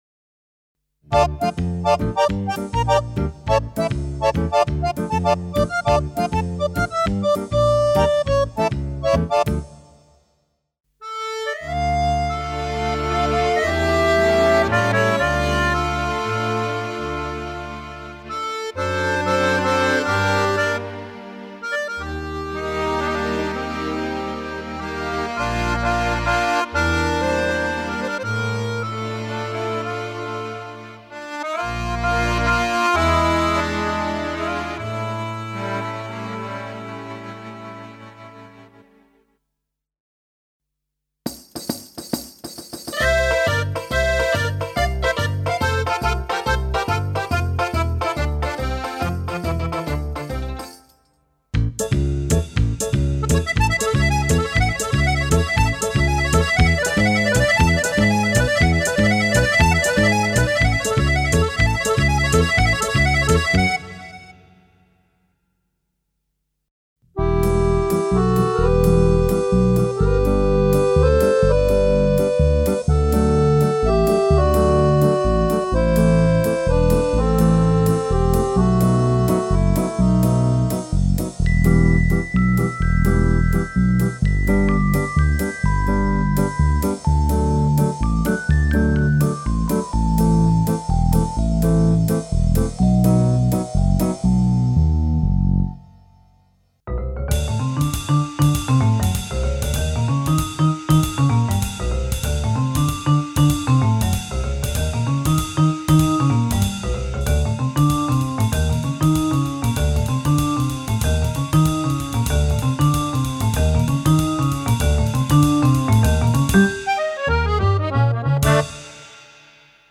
Many of the standart pieces for accordion works with that set.
01 tango: deep piano on the left, deep bandoneon or masterbar switched strings on the right
03 solo bandoneon on both sides
04 meditative flair: with smooth bass, guitar + closed HH and soft cassotto accordion
05 marine flair: with smooth bass, guitar + sea shore and "Marine Accordion"
06 old fashioned dance band: with bass guitar + shaker, vintage organ + closed HH and soft cassotto accordion, masterbar switches organ
08 the same bass combi with acoustic guitar chords and a mellow tremolo accordion, masterbar switches organ
10 french comping: smooth bass guitar + ride in the left, soft accordion in the right, masterbar adds a "celesta"
11 south america: bassguitar + bassdrum and high nylon guitar + ride for south american rhythms, a friendly tremolo on the right, masterbar switches to a brass section
12 my italian folk register: acoustic bass with tamorra "closed" + high banjo + open tamorra in the left, mandolin + tremolo in the right. masterbar muted the mandolin for verses.
13 sentimental russian: bowed bass + strings in the left, russian harmonica in the right, masterbar adds "balalaika" (mandolin)